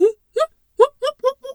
pgs/Assets/Audio/Animal_Impersonations/zebra_whinny_07.wav at master
zebra_whinny_07.wav